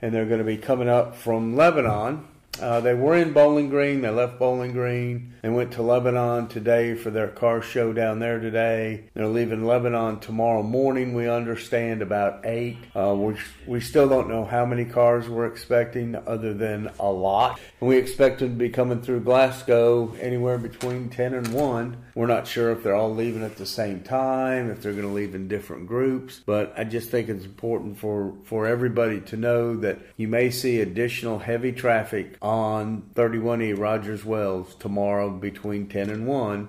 Glasgow Police Chief Guy Howie spoke on what residents should expect.